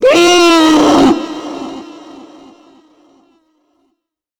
Divergent / mods / Soundscape Overhaul / gamedata / sounds / monsters / psysucker / die_3.ogg
die_3.ogg